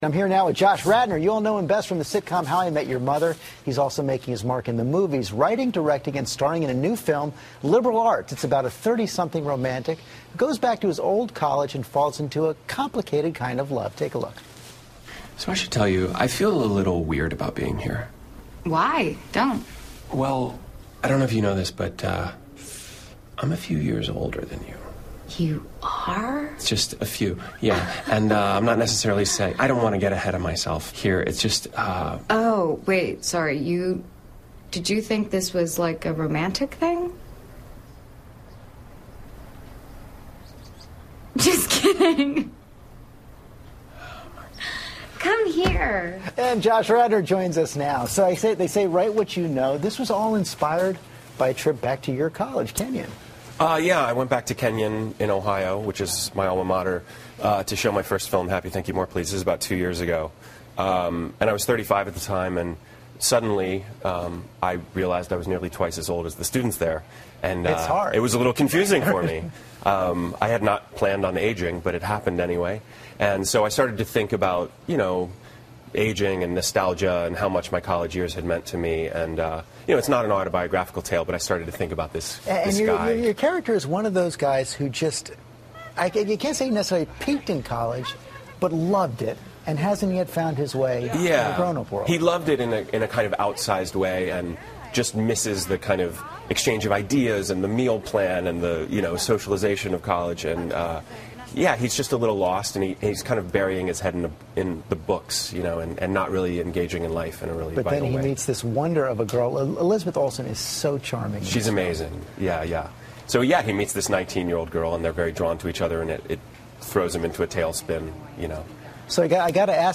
访谈录 2012-09-12&09-14 乔什.拉德诺谈校园爱情 听力文件下载—在线英语听力室